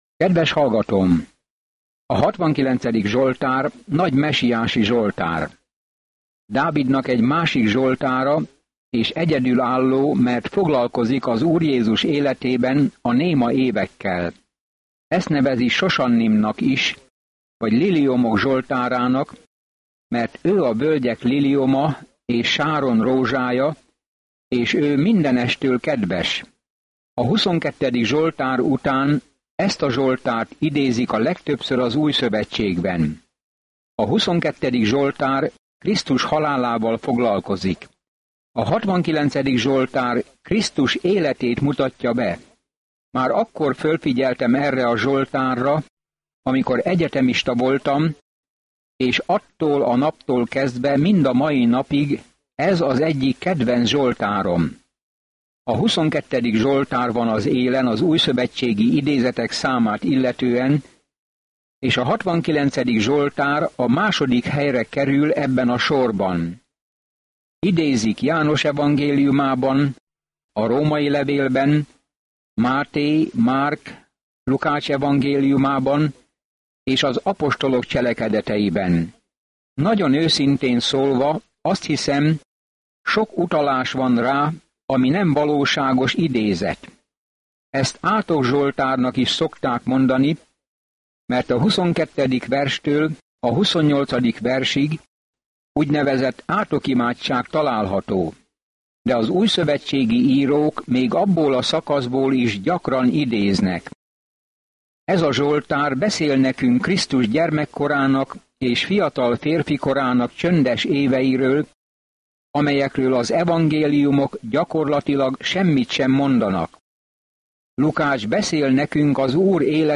Napi utazás az Zsoltárok keresztül, miközben hallgatod a hangos tanulmányt, és olvasol válogatott verseket Isten szavából.